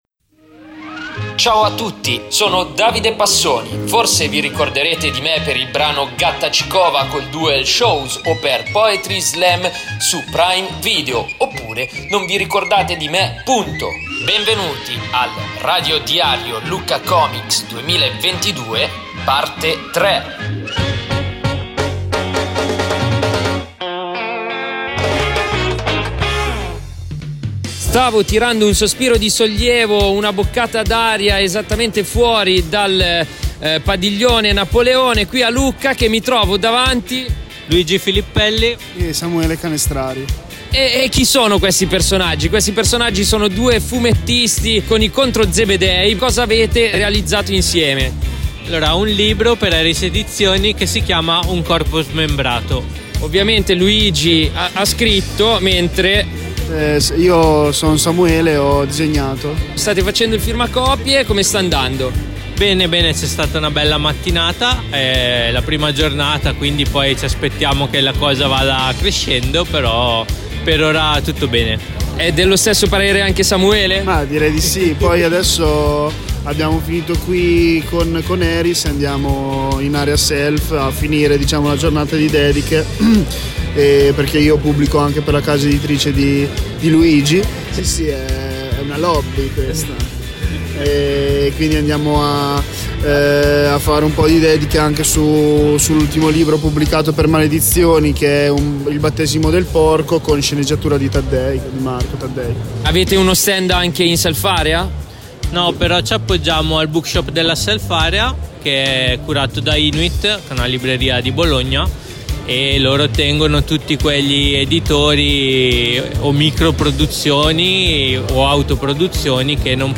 Radio Diario Lucca | voci, suoni dal Lucca Comics & Games – Parte 3